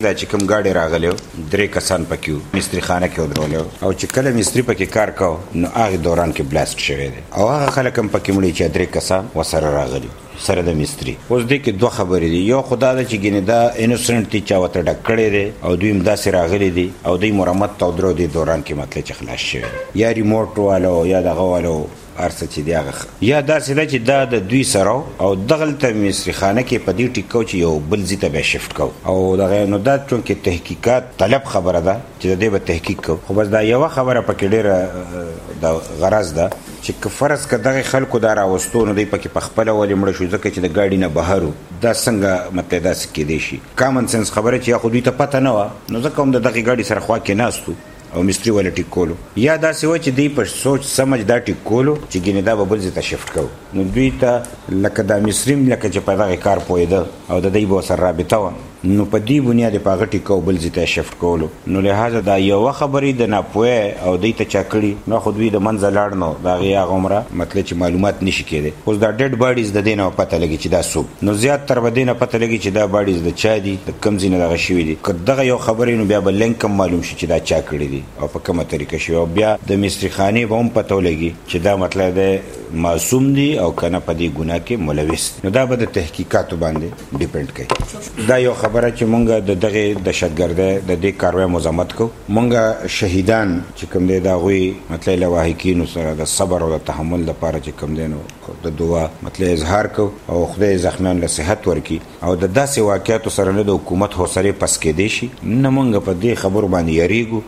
دخيبرپښتونخوا داطلاعاتو وزير ميا افتخار حسين دپيښې دتفصيلاتو بيانولو په وخت ډيوه ريډيو ته وويل په کوم گاډي کې چې چاؤدنه شوې ده په هغې کې درې کسان ناست وؤ او يو پکې دگاډو مستري هم کار کولو، چې دغه څلور واړه په چاؤدنه کې مړۀ شوي دي